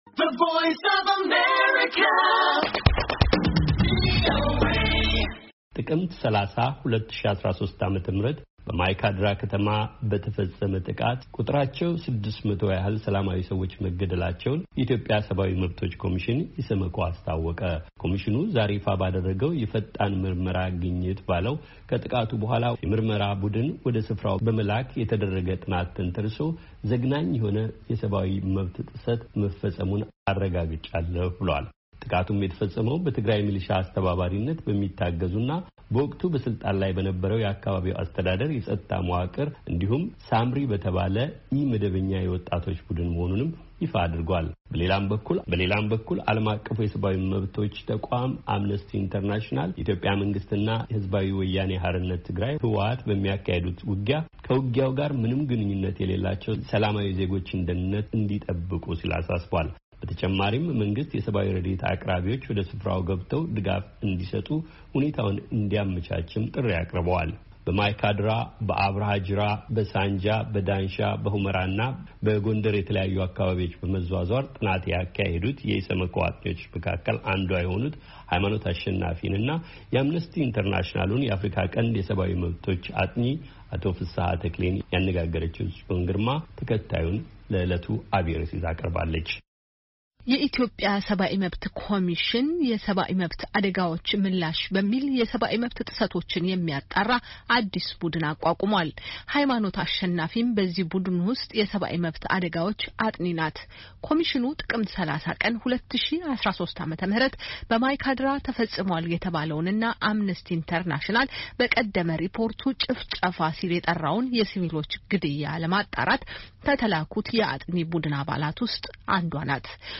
በማይካድራ ተገኝተው ምርምር ያደረጉ አጥኚ አነጋግረናል